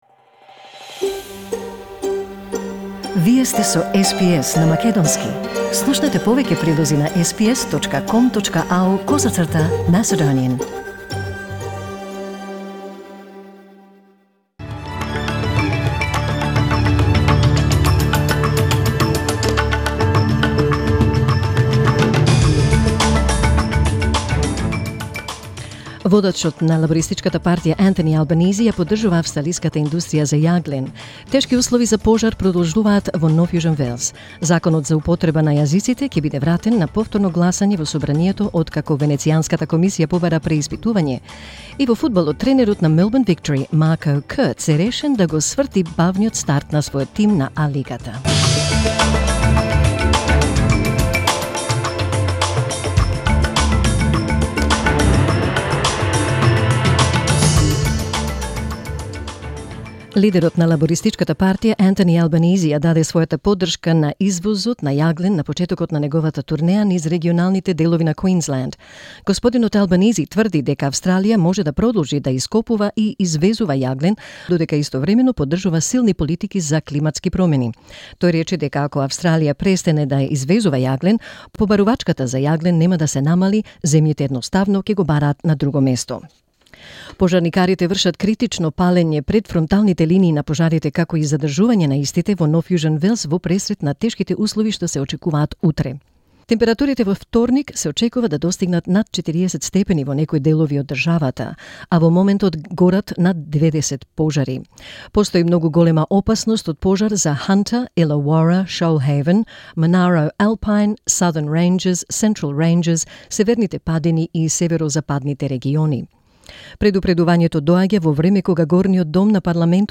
SBS News in Macedonian 9 December 2019